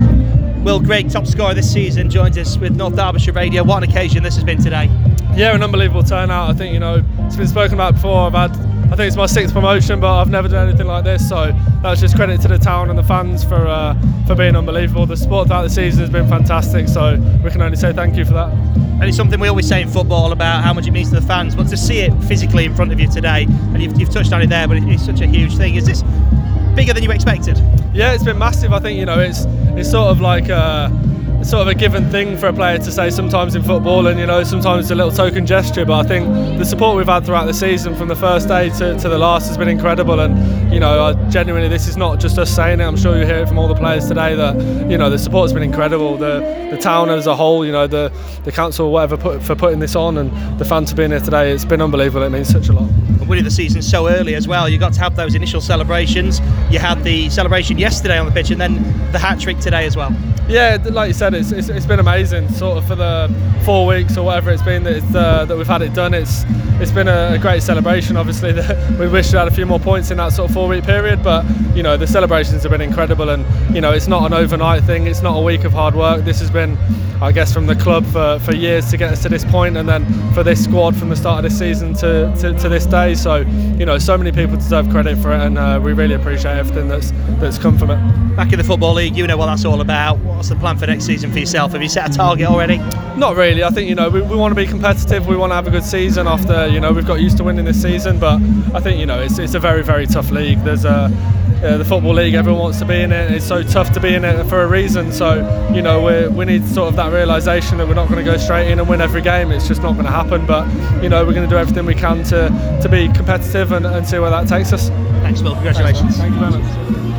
Chesterfield celebrated their return to the Football League with an open top bus parade which ended at the Town Hall as thousands of fans joined the team to share in the joy of their amazing National League triumph.